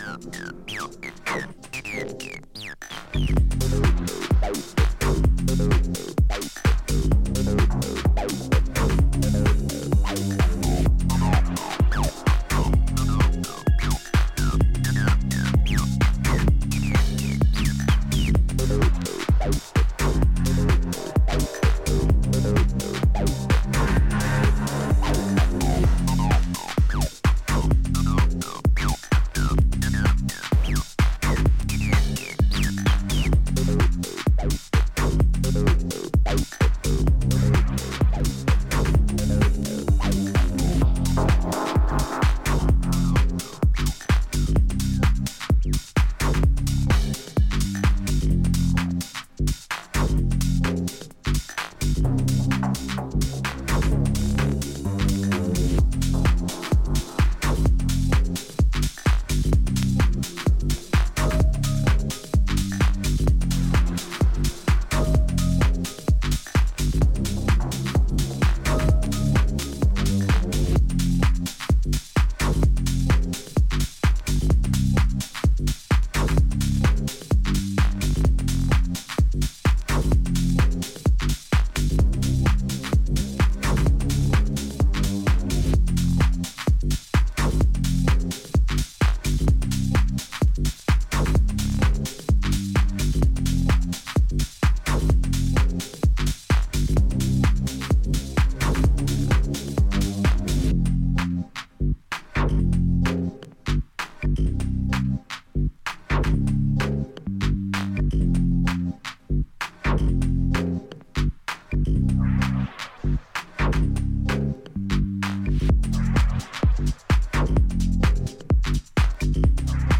tried and trusted minimal house power
Minimal , Tech house